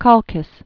(kŏlkĭs)